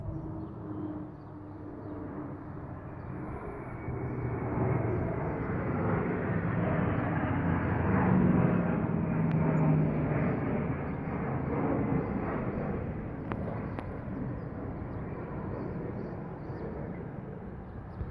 AIRPLANE JET FLYING
描述：Into an Air France Boeing 777300 ER, between Reunion Island and Paris. We can ear people talking and moving into the plane. At about 1’20’’ the engines slow down.
标签： cabin airplane aviation ambient plane drone Boeing transportation jet flight 777
声道立体声